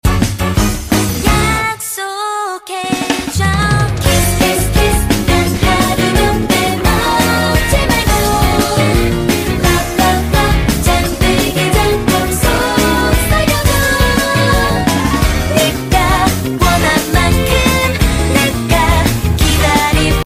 Kiss Kiss Kiss💋 Sound Effects Free Download